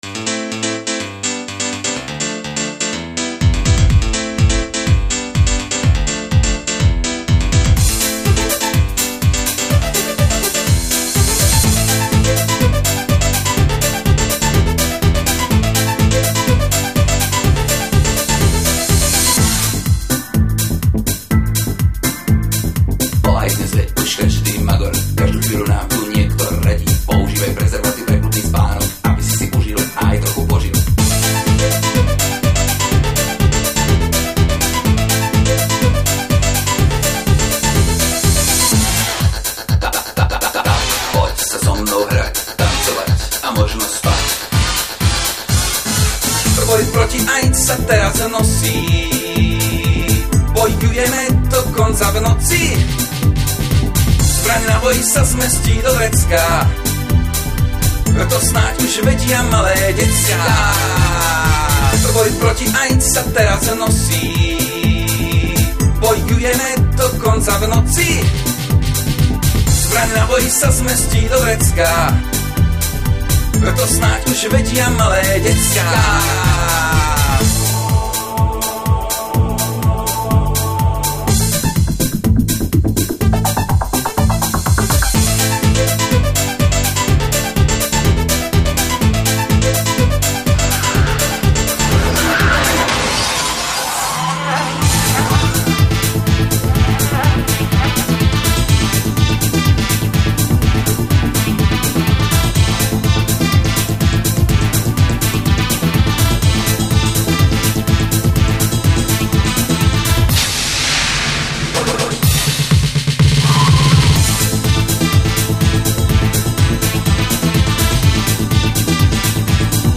PesniΦka bola myslenß ako zvukovΘ logo akcie.
Obe pesniΦky aj ostatnΘ ukß╛ky v tomto webovom hniezdoΦku vznikli na be╛nom poΦφtaΦi s kartou AWE 32 + 8mega RAM (V tom Φase okolo 9000.- Sk s DPH) a prφdavn²m wavetable modulom Yamaha DB50XG (v tom Φase okolo 6000.- Sk s DPH) a╛ po mastering st⌠p na CD.